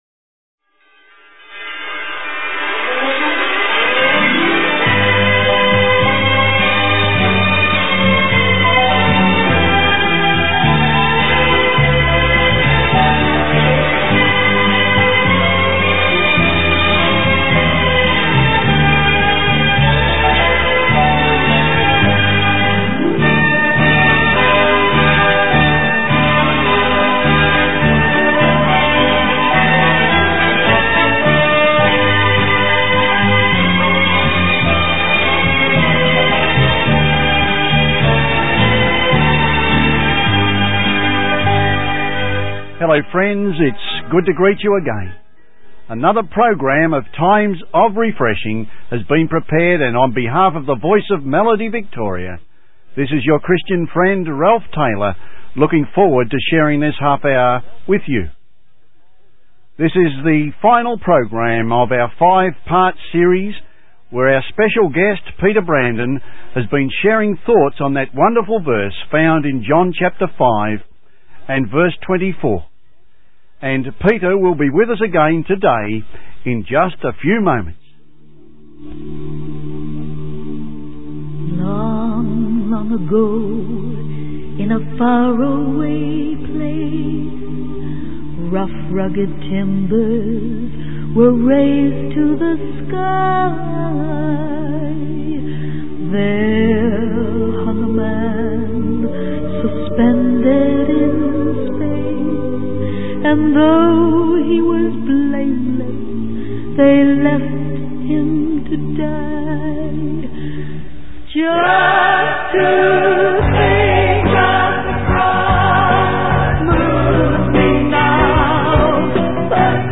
The sermon concludes with a heartfelt invitation to embrace faith in Jesus for everlasting life.